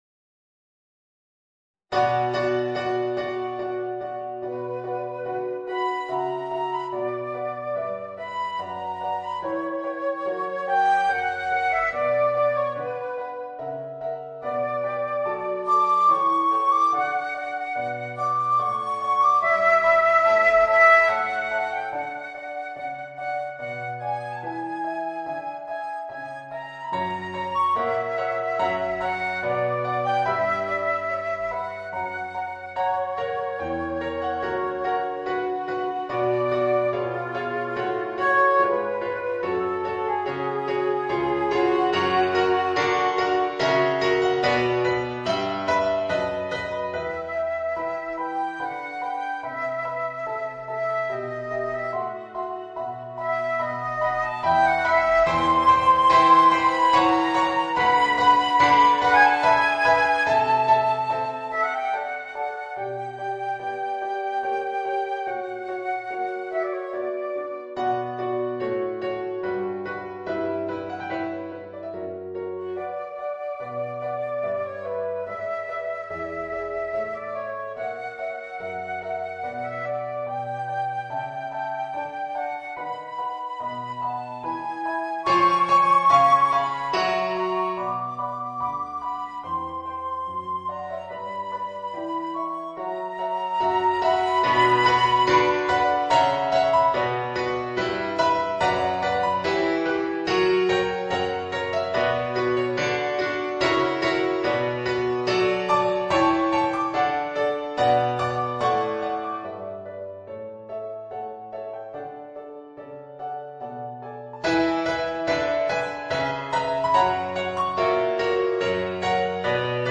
Voicing: Flute and Organ